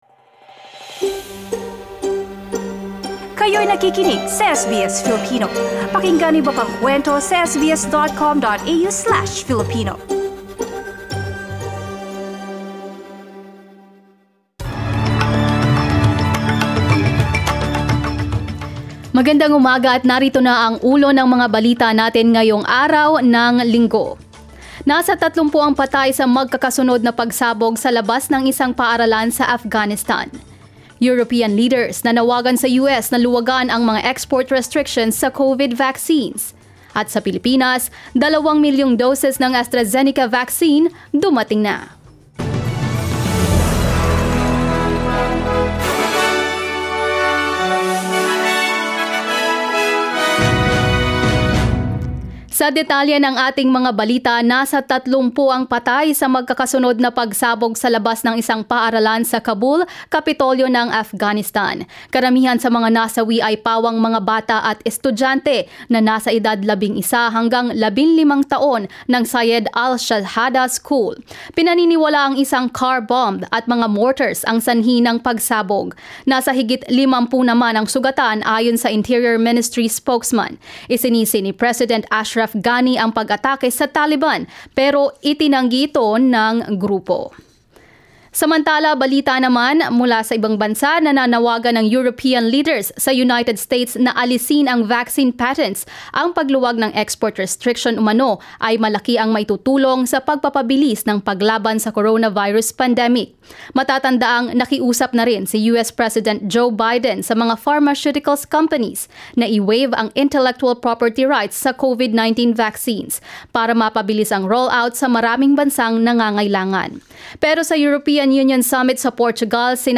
SBS News in Filipino, Sunday 9 May